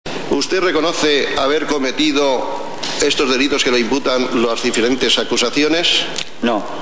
Durante el juicio oral de 1997 (2) A Juan Luis De la Rua se le ve el plumero Juan Ignacio Blanco en Vía Digital